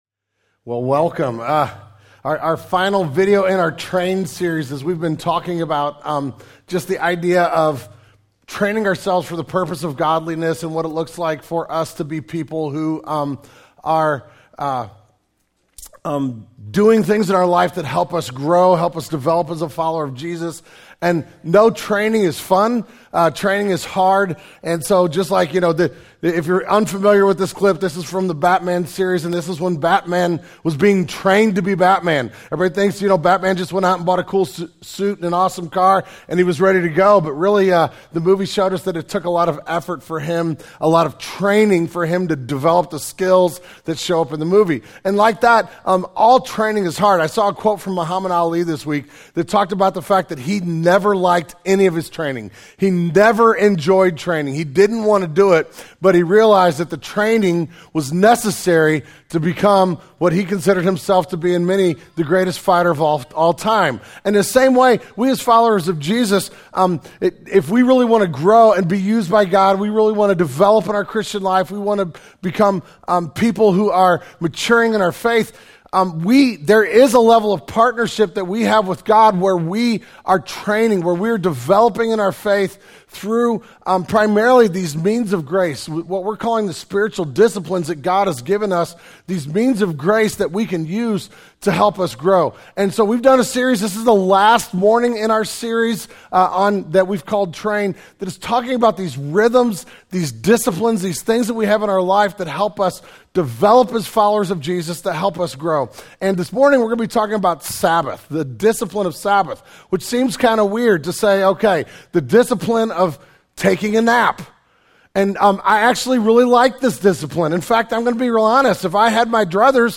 The June 2016 Sermon Audio archive of Genesis Church.